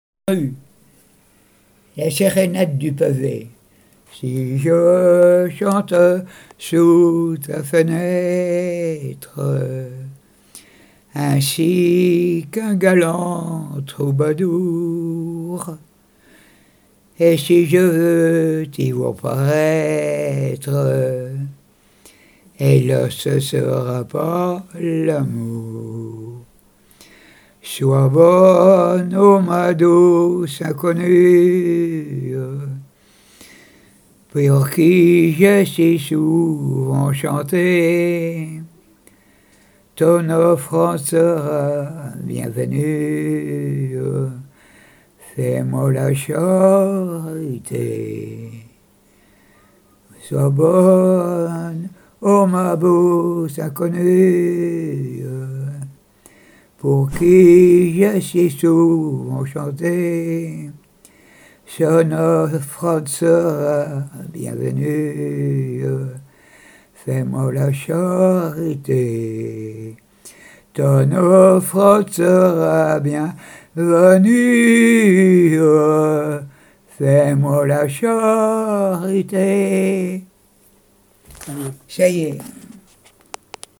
Mémoires et Patrimoines vivants - RaddO est une base de données d'archives iconographiques et sonores.
Enquête EthnoDoc et Arexcpo dans le cadre des activités courantes des membres des associations
Pièce musicale inédite